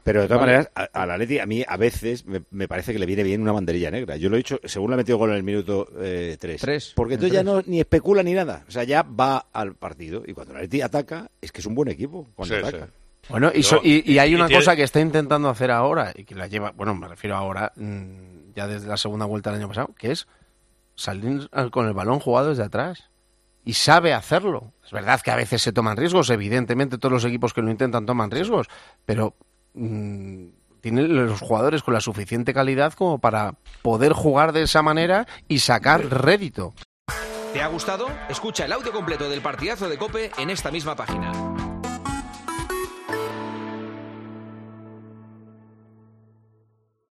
AUDIO: El tertuliano del programa dirigido por El Partidazo de COPE valoró el partido realizado por el equipo dirigido por Simeone contra el Celtic.